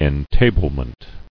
[en·ta·ble·ment]